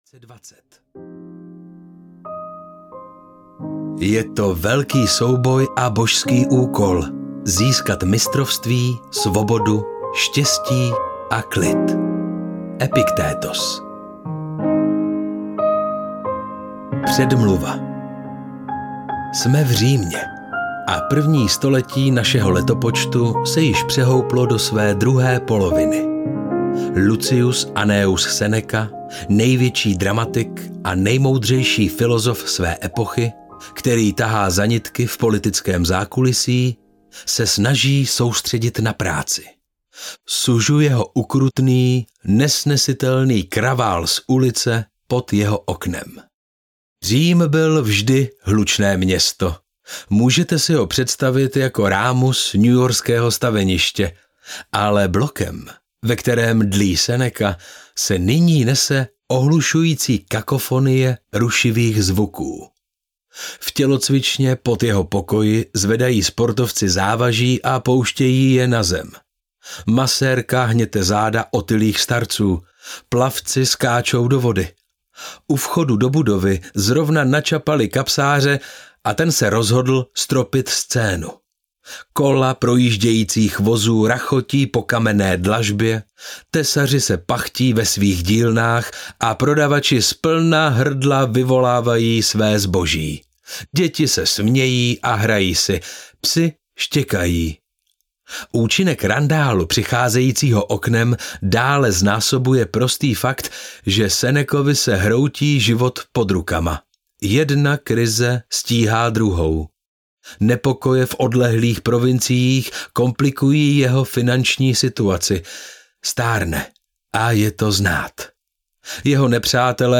Klíčem je klid audiokniha
Ukázka z knihy